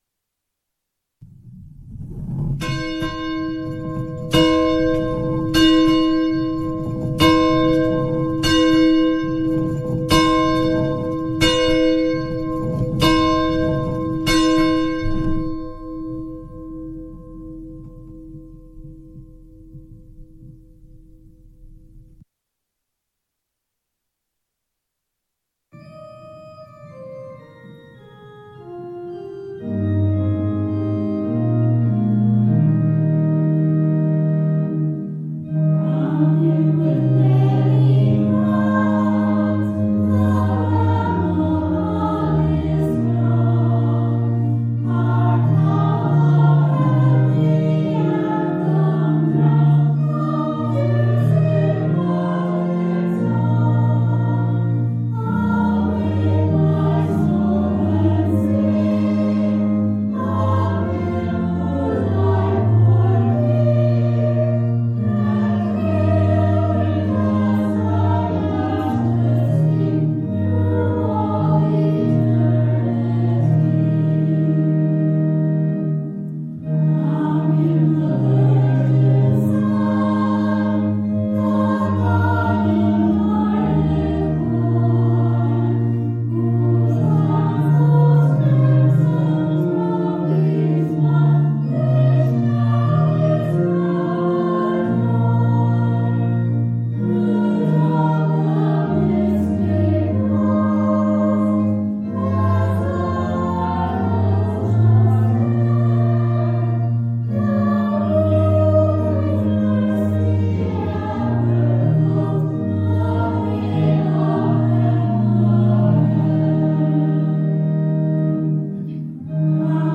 Zion Internet Service May 2nd, 2021